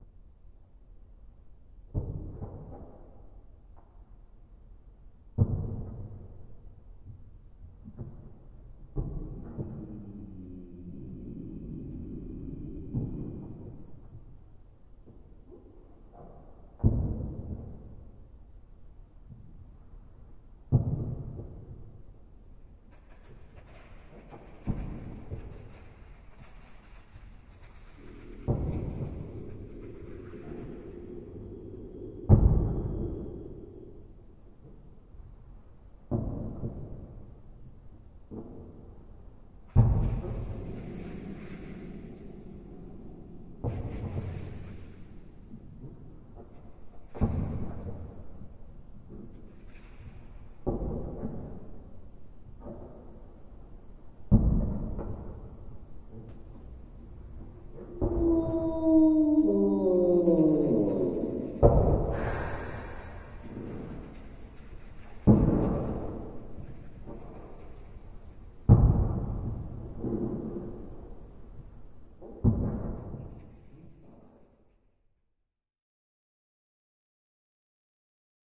描述：怪物从远处走来的声音。用门的声音、碎屑的声音、打破玻璃的声音和脚步声创造出来的